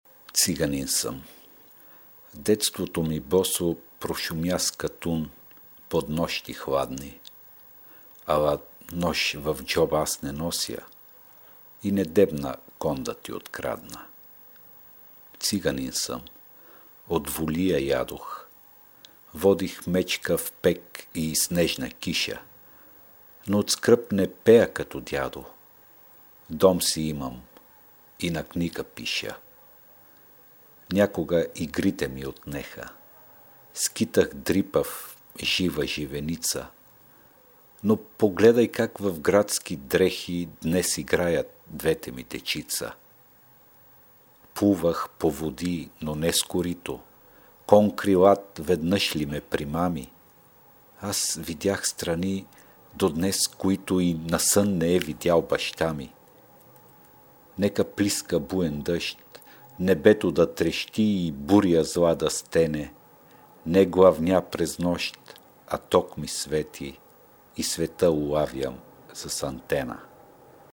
Oral Literature
subjektora/kotora Bulgarien, recitacija, poezija, ramome literatura, poemo, Balkano